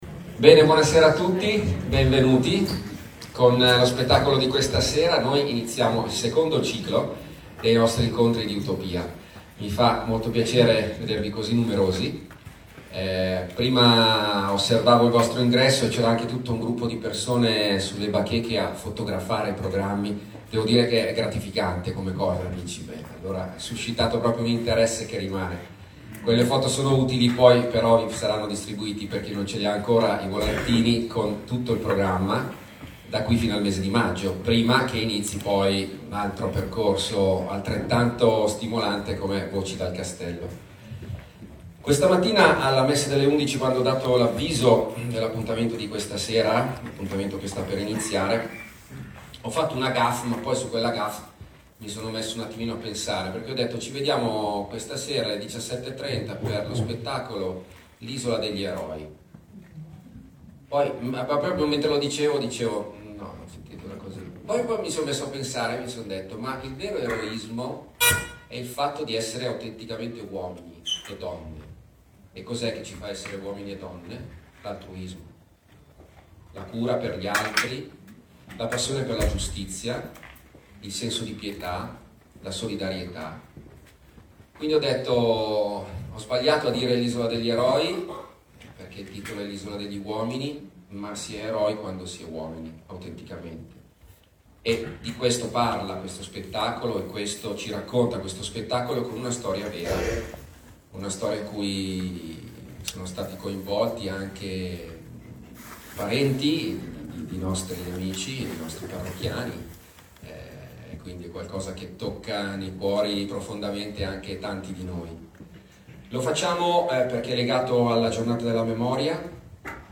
Introduzione